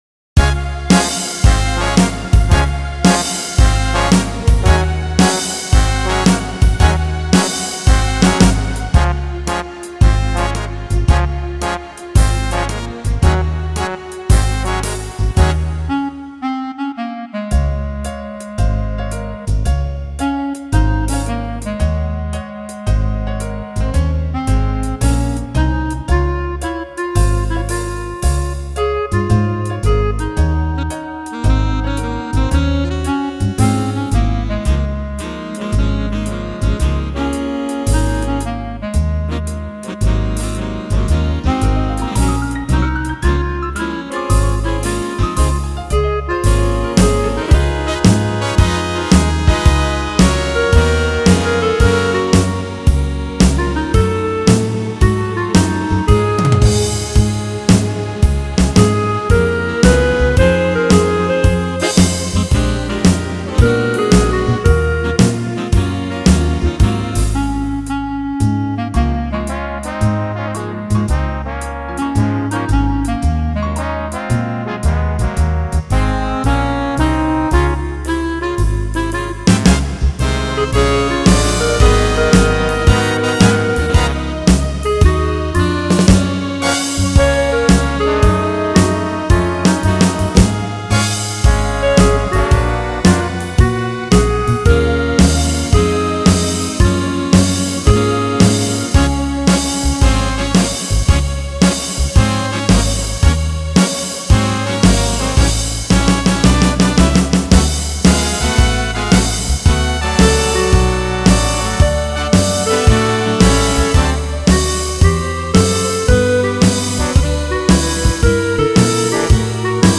thy68eohkk  Download Instrumental